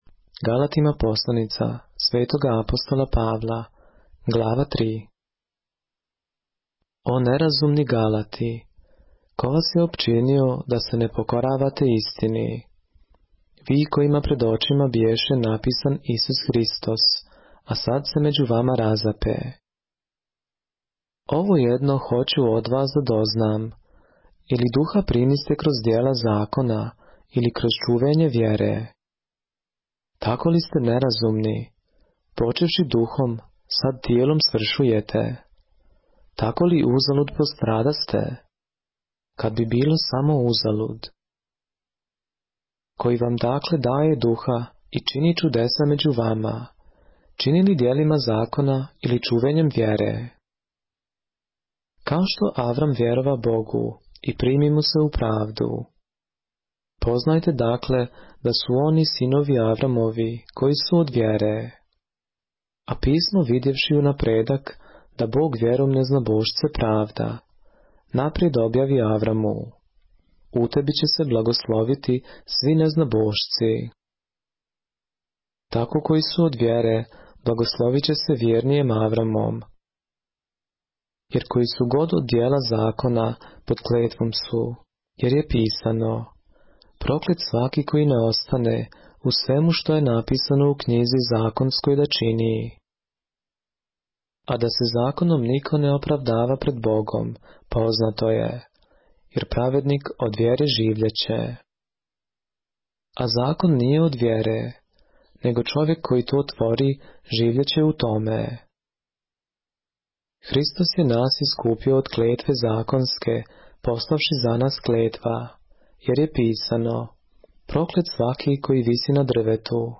поглавље српске Библије - са аудио нарације - Galatians, chapter 3 of the Holy Bible in the Serbian language